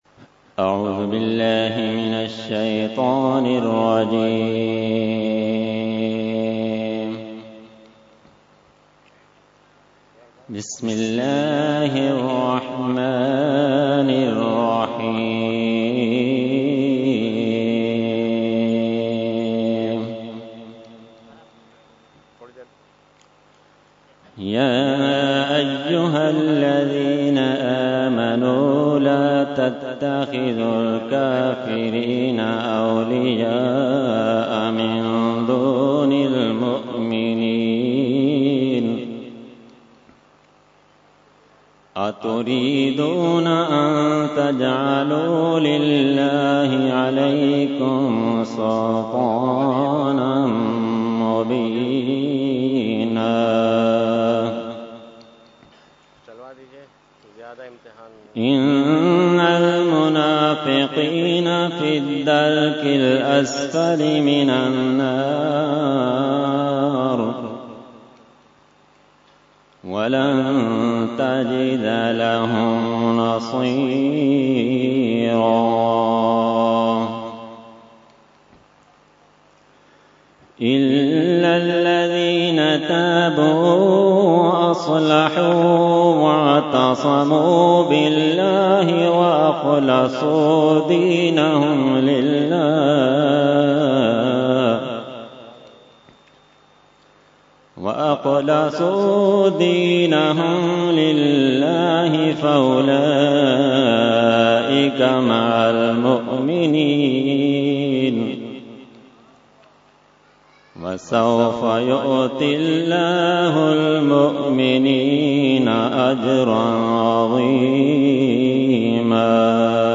Qirat – Urs Makhdoom e Samnani 2017 – Dargah Alia Ashrafia Karachi Pakistan